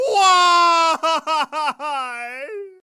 Yo Mama Crying Sound Effect Free Download
Yo Mama Crying